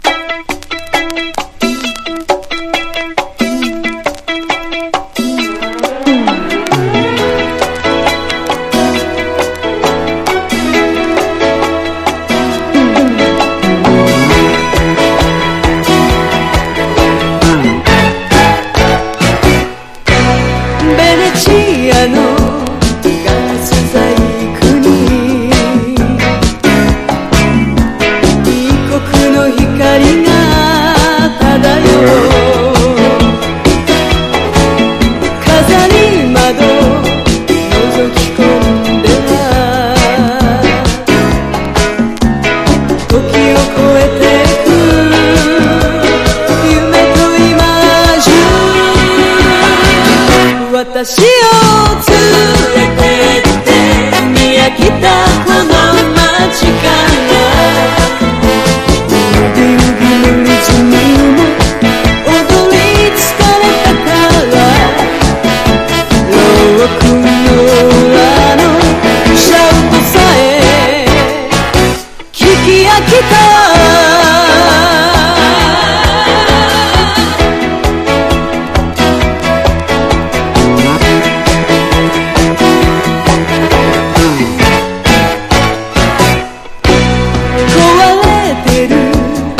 CITY POP / AOR
ロスアンゼルス録音 最強のミュージシャンによるタイトなサウンド